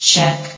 sound / vox_fem / check.ogg
CitadelStationBot df15bbe0f0 [MIRROR] New & Fixed AI VOX Sound Files ( #6003 ) ...